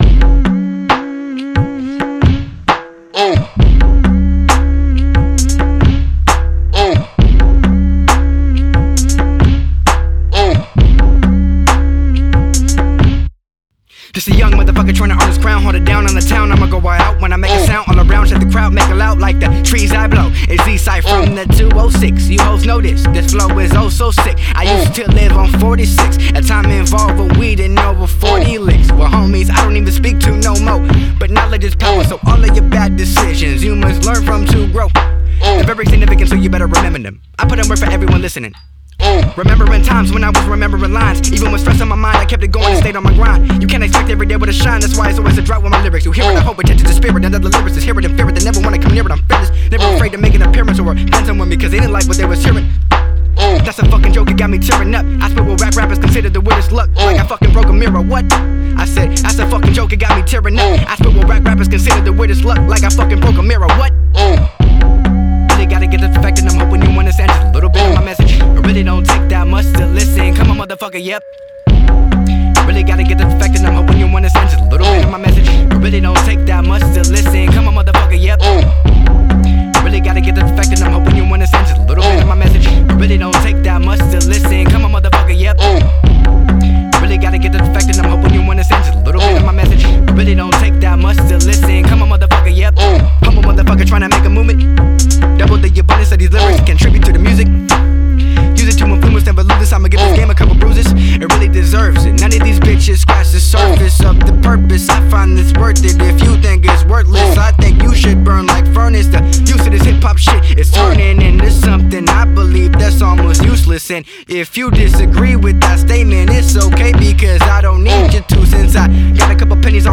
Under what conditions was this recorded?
Recorded at AD1 Studios, Seattle, Washington